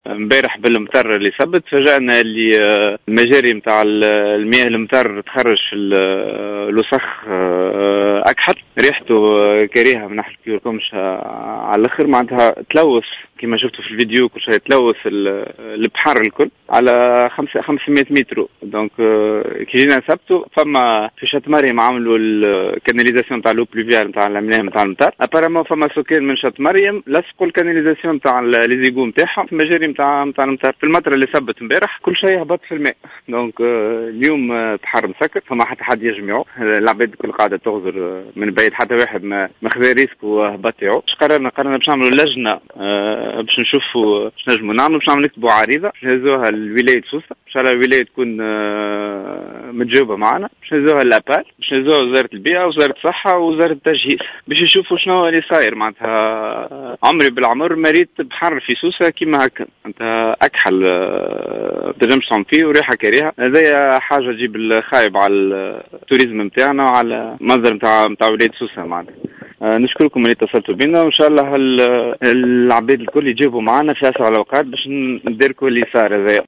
شاهد عيان يتحدث تحميل المشاركة علي